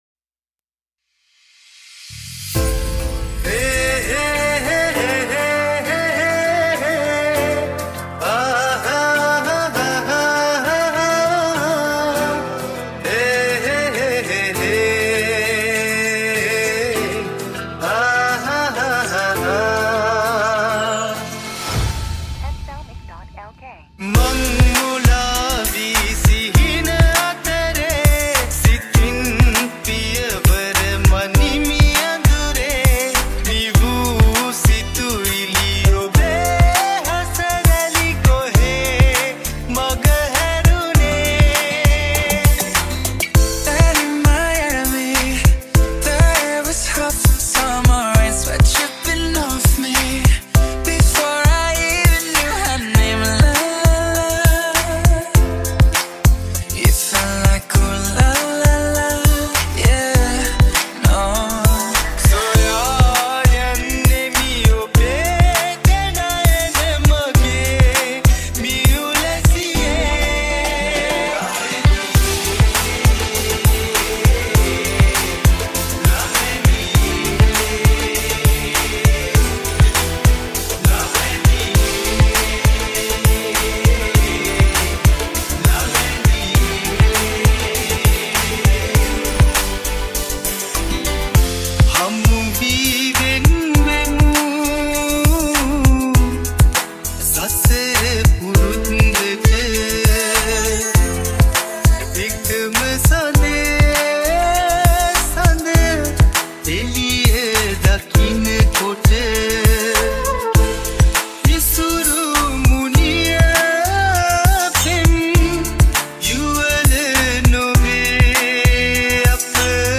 Remix New Song